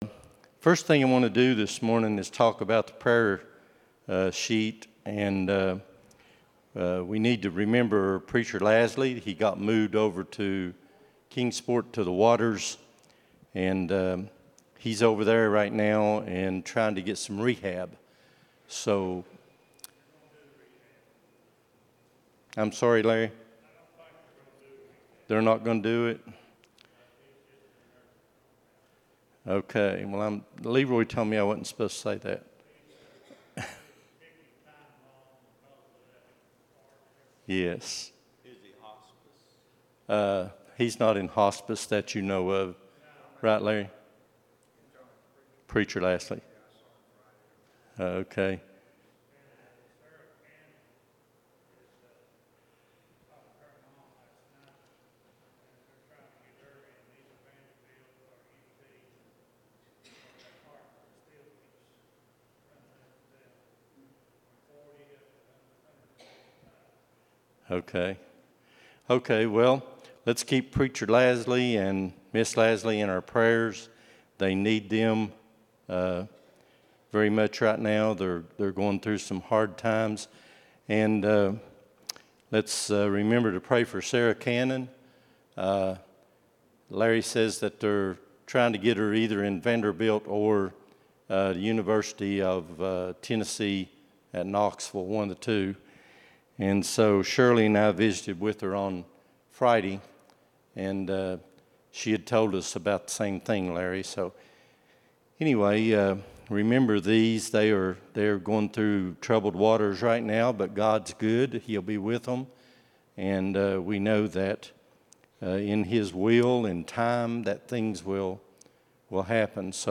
Sunday School Lesson
at Buffalo Ridge Baptist Church in Gray, Tn.